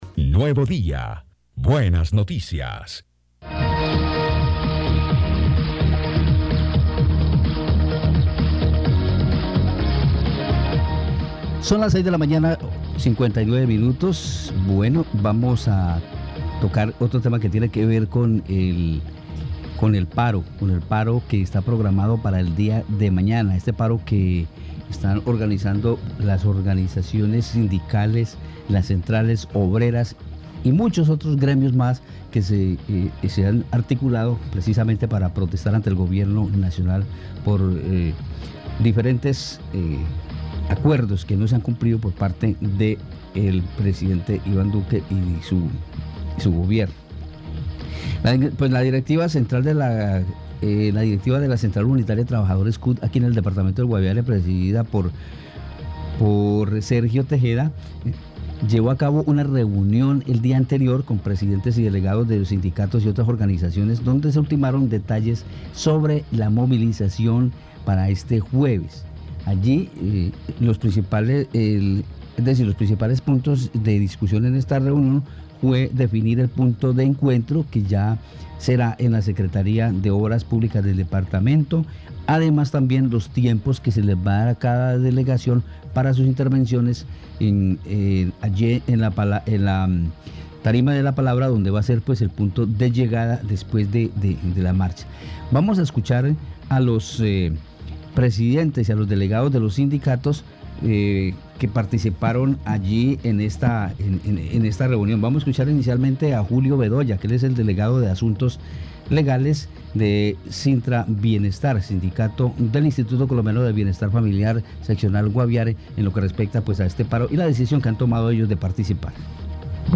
Escuche las voces de los voceros de los sindicatos del Guaviare.